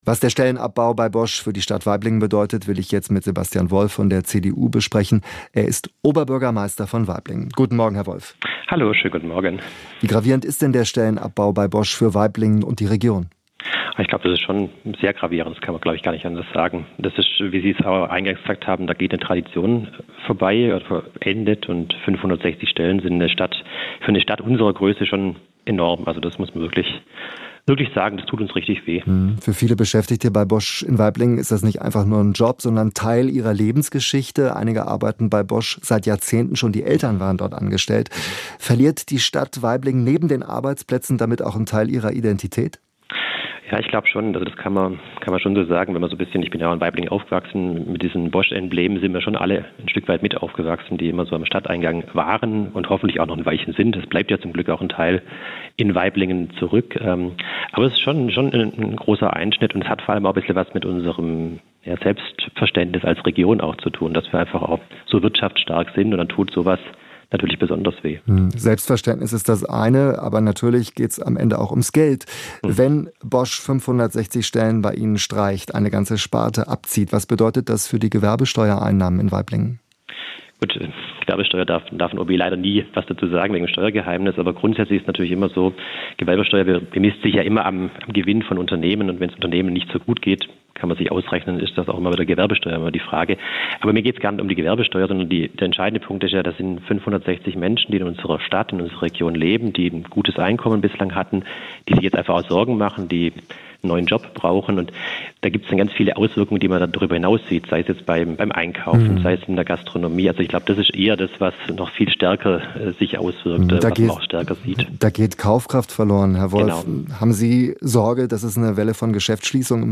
Die Schließung ist auch für die Stadt Waiblingen ein großer wirtschaftlicher Einschnitt. Zwar nennt Oberbürgermeister Sebastian Wolf (CDU) im Interview mit SWR Aktuell keine Zahlen, wie groß der Einbruch bei der Gewerbesteuer sein wird.